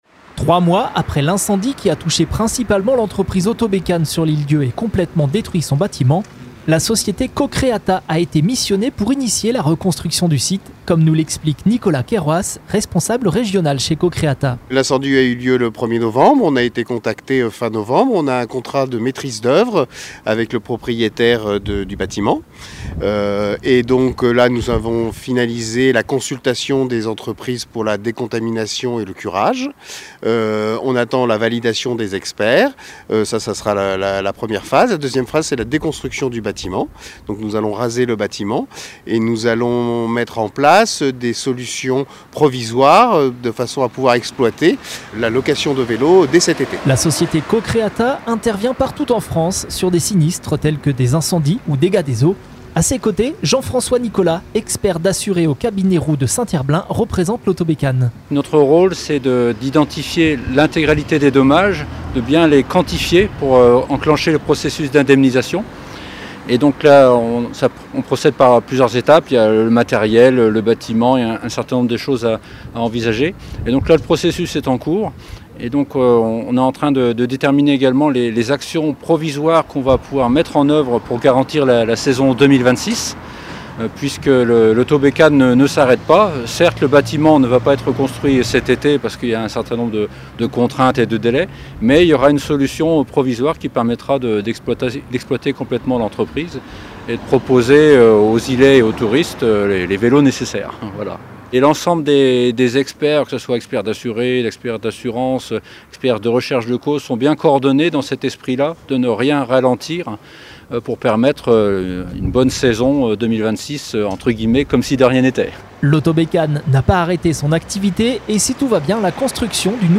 reportage-autobecane-fev2026.mp3